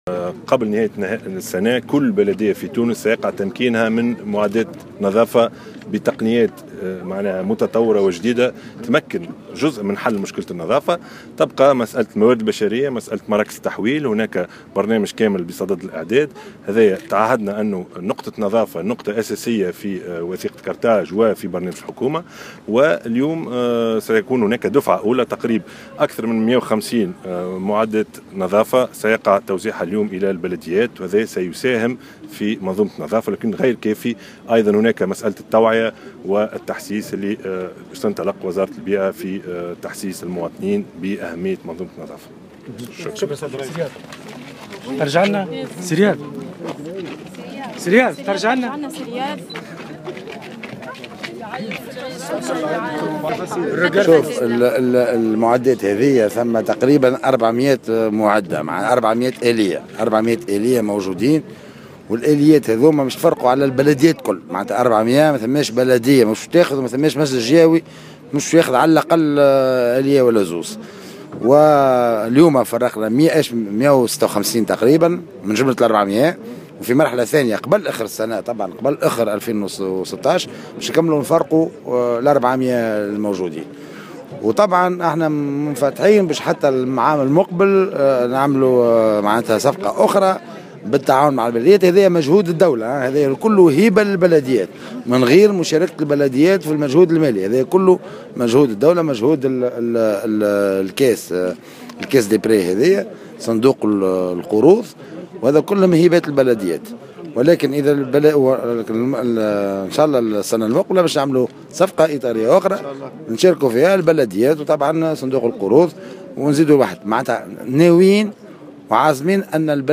وقال في تصريحات صحفية انه تم توزيع اليوم 156 آلية من مجموع 400 آلية نظافة مبرمجة بقيمة جملية تقدر بـ 56 مليون دينار.